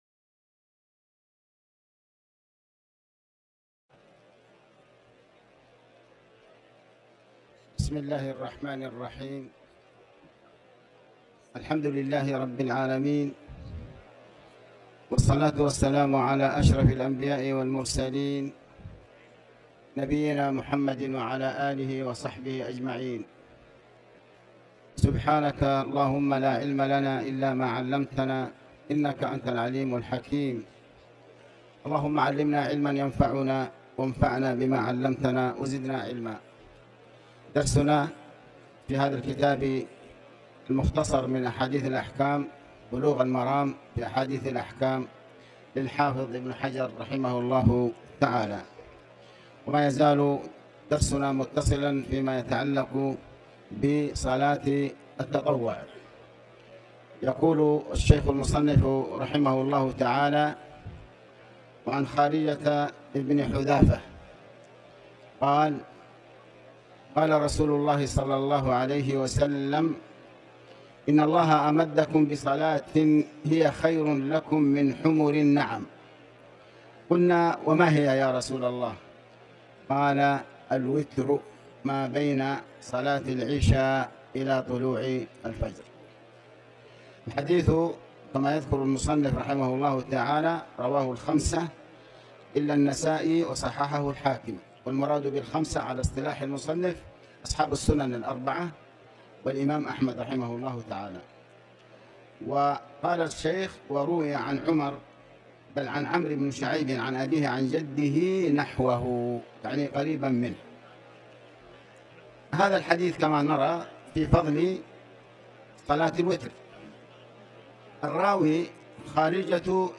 تاريخ النشر ٢ جمادى الآخرة ١٤٤٠ هـ المكان: المسجد الحرام الشيخ